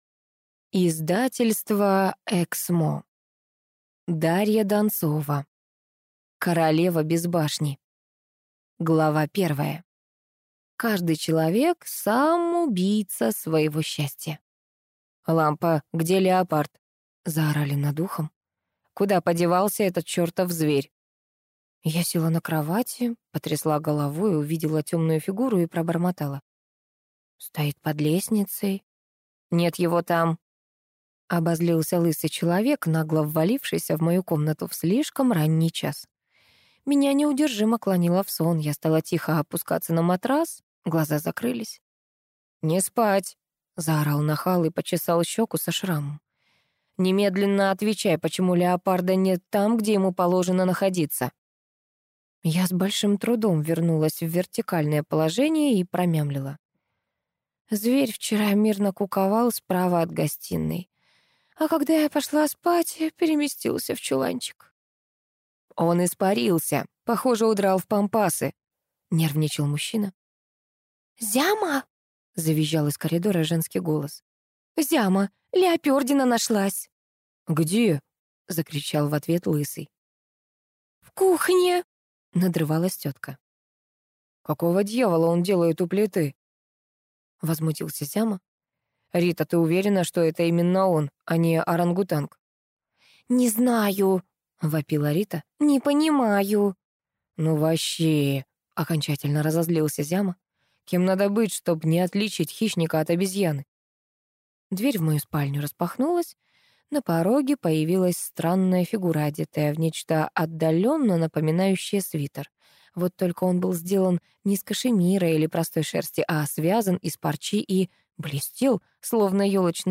Аудиокнига Королева без башни | Библиотека аудиокниг
Прослушать и бесплатно скачать фрагмент аудиокниги